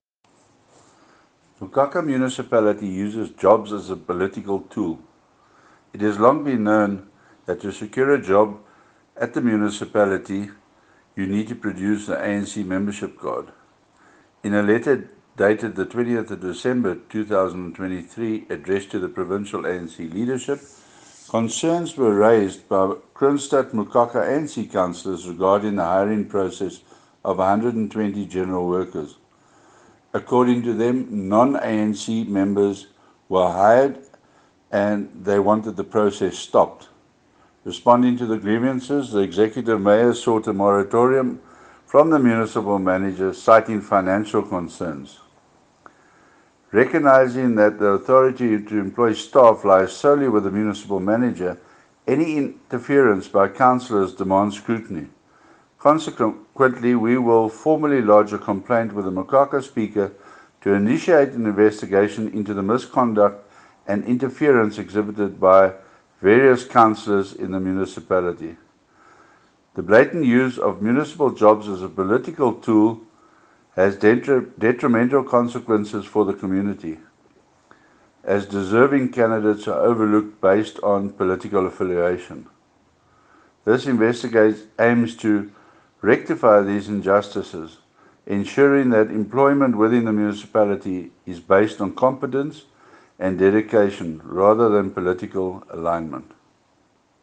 English soundbite by Cllr Chris Dalton.